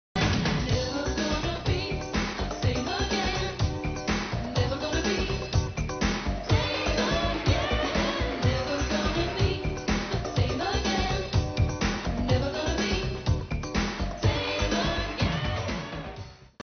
great unknown dance number